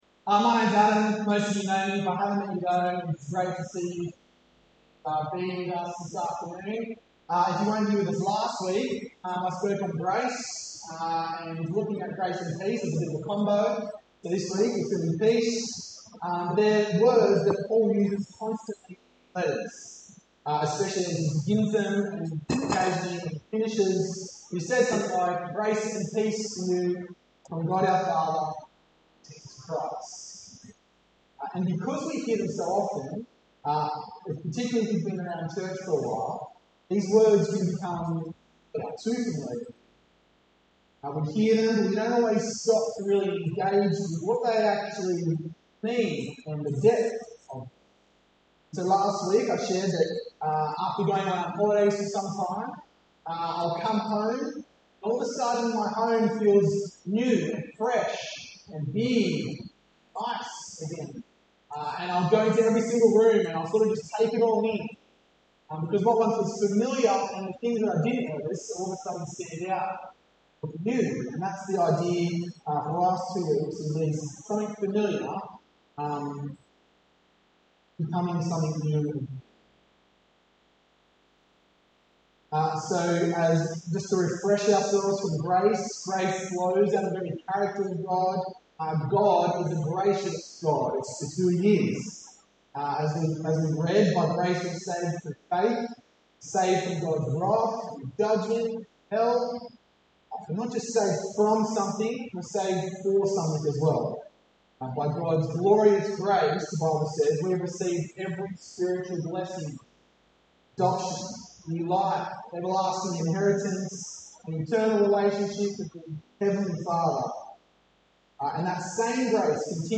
Village Church Home I'm New Who is Jesus Sermons Peace January 26, 2026